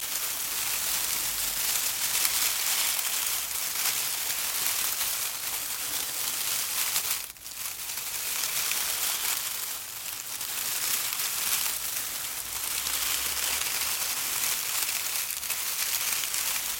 Шорох в кустах